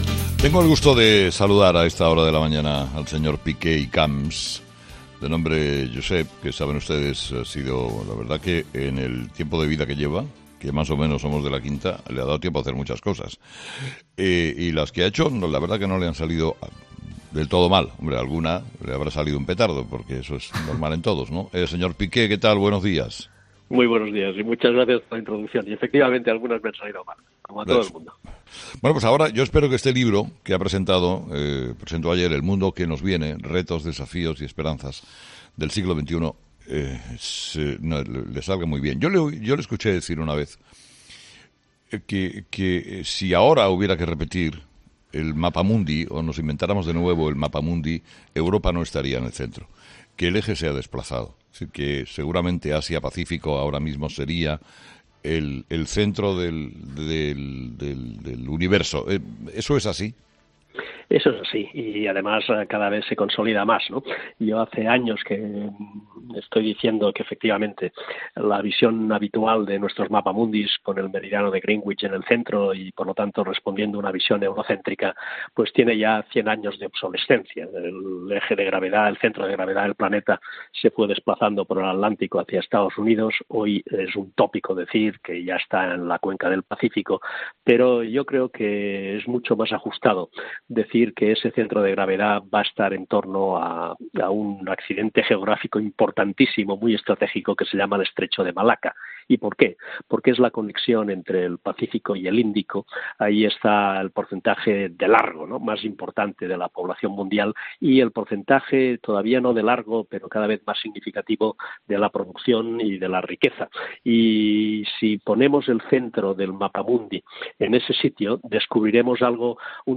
Entrevista a Josep Piqué, exministro de Exteriores con Aznar y autor del libro “El mundo que nos viene”